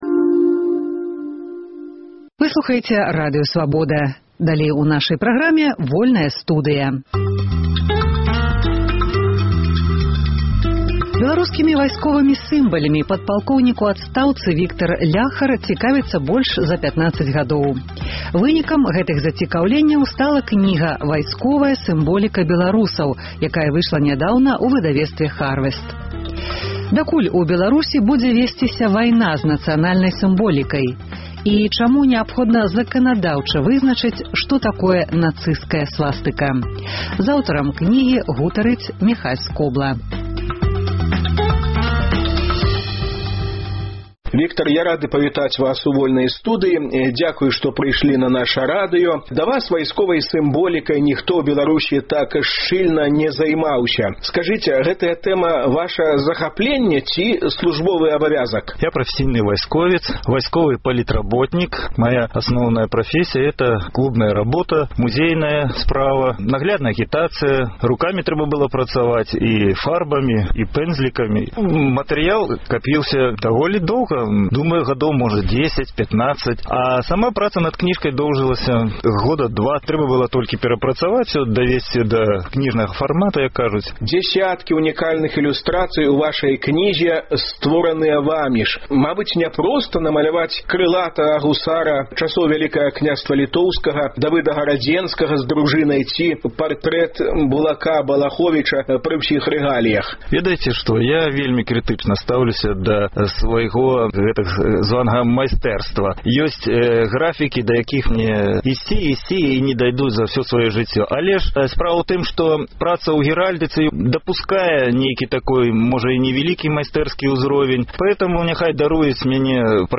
Гутаркі